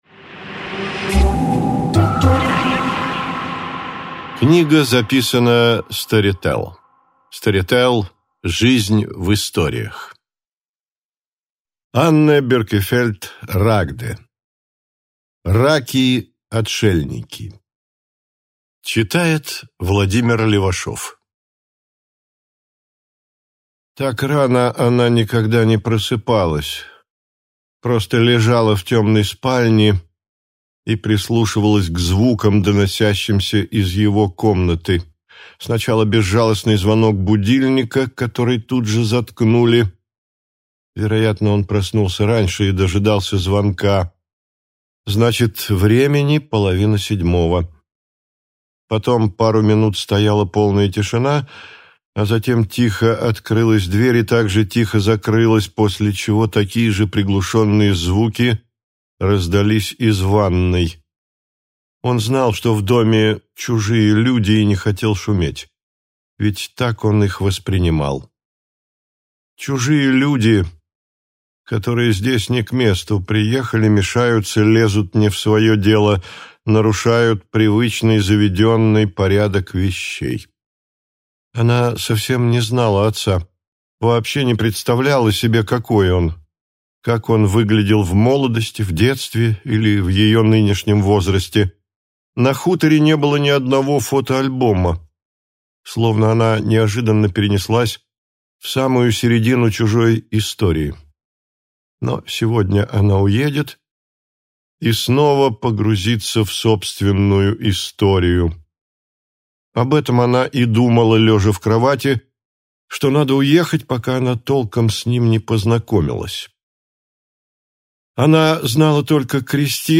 Аудиокнига Раки-отшельники | Библиотека аудиокниг